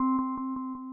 Pluck - Crash.wav